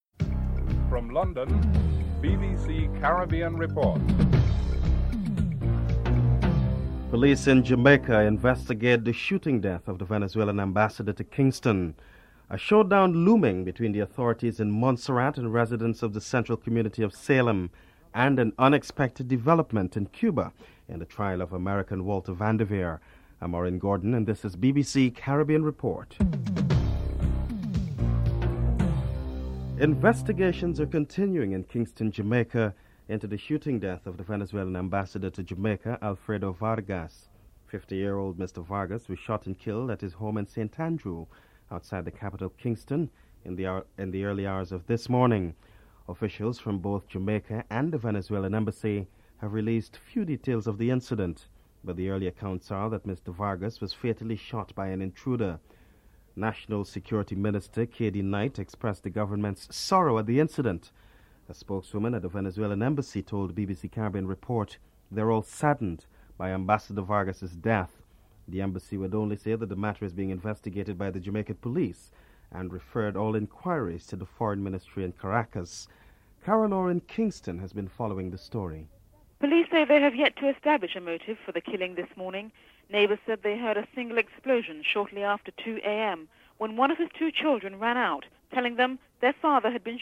1. Headlines (00:00-00:27)
6. Anthony Hilton, Jamaica Minister of State for Foreign Affairs says that the Caribbean should not be reading too much into the recent statement by European Commissioner Pinheiro. Jamaica Minister of State for Foreign Affair, Anthony Hilton is interviewed (11:08-12:21)